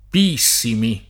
[ pi- &SS imi ]